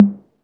808-Tom3.wav